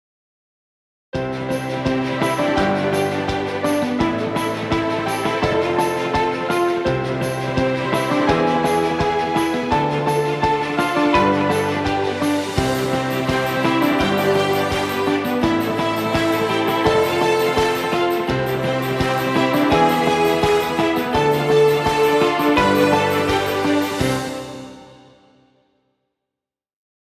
energetic motivational corporate track with positive mood.
Background Music Royalty Free.